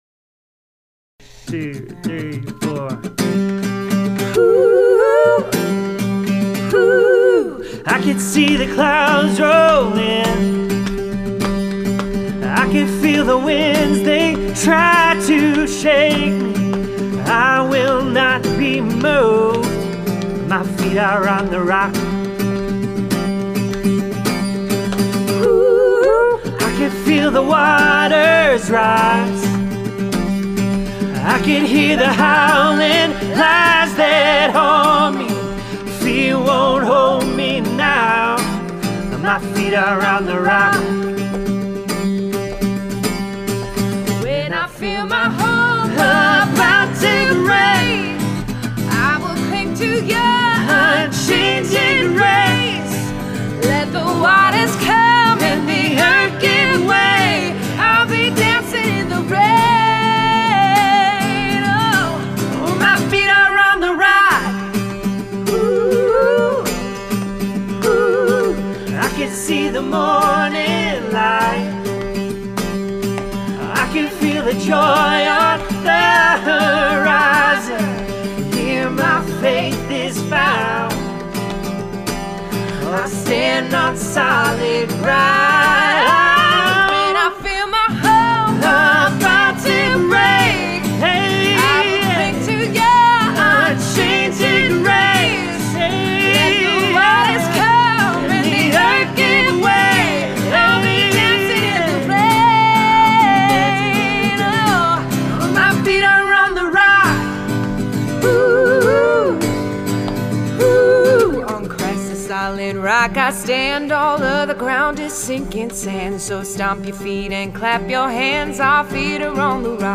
live in our studio